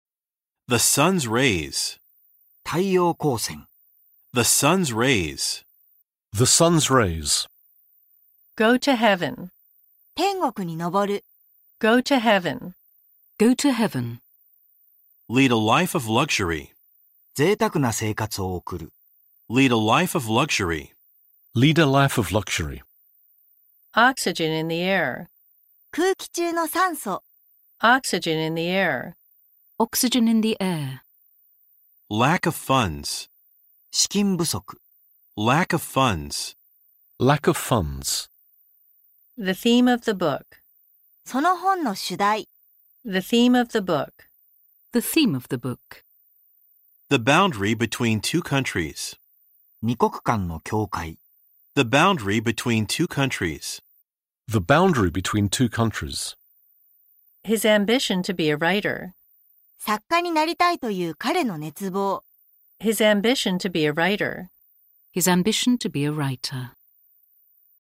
システム英単語（５訂版）：本書の音声はそれぞれの単語、フレーズを米音と英音の両方で収録しています。以下の音声フレーズは、①アメリカ英語→②日本語訳→③アメリカ英語→④イギリス英語で読み上げています。
Rの発音：アメリカ英語は巻き舌のしっかりした発音になりますが、イギリス英語はRの発音は基本的にしません。
例文でもアメリカ人の話す英語はwriterが「ライー」のように聞こえます。イギリス人はしっかりTの発音をします。